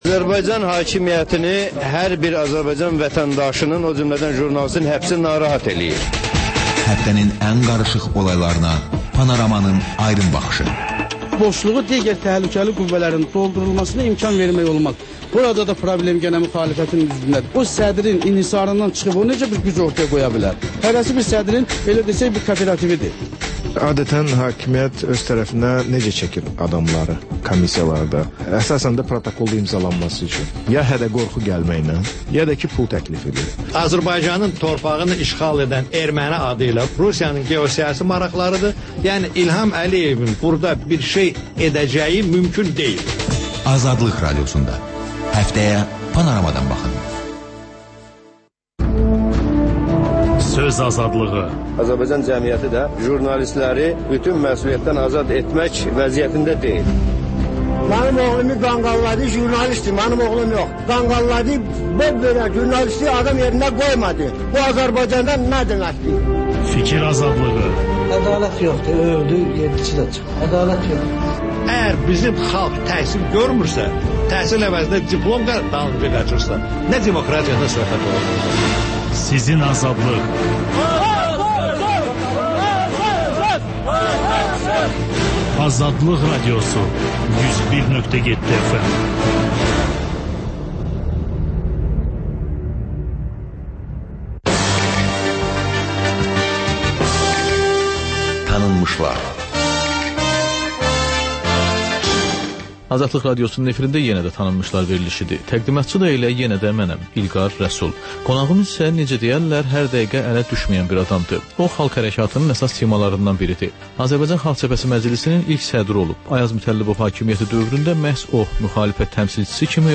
Ölkənin tanınmış simaları ilə söhbət (Təkrar)